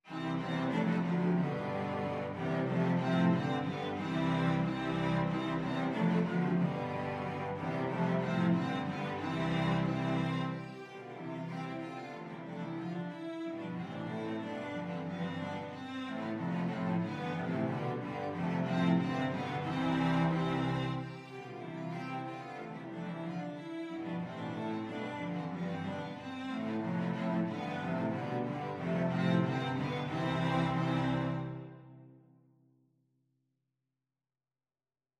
Christmas Christmas Cello Quartet Sheet Music Ding Dong Merrily on High
Free Sheet music for Cello Quartet
Cello 1Cello 2Cello 3Cello 4
C major (Sounding Pitch) (View more C major Music for Cello Quartet )
2/2 (View more 2/2 Music)
Merrily =c.92
Traditional (View more Traditional Cello Quartet Music)